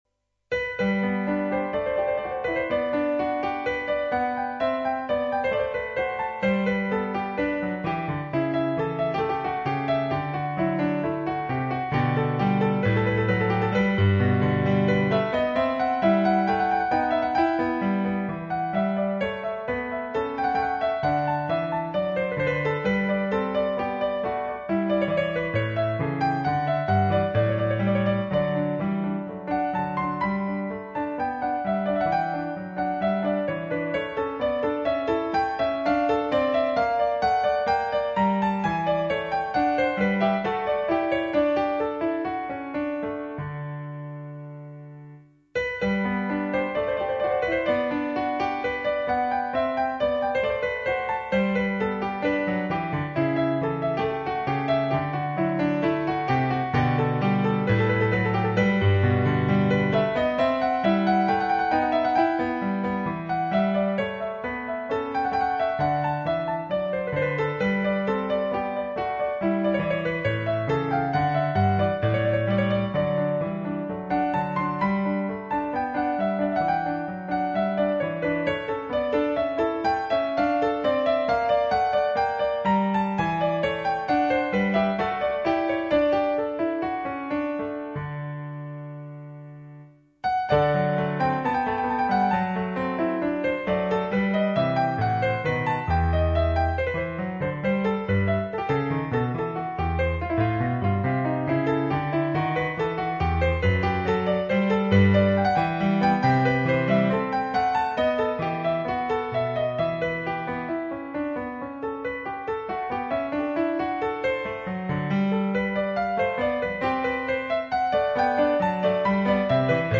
第５番 BWV 816 Allemande 演奏は心を表わすとはよく言ったもの。余裕のない弾き方に呆然・・・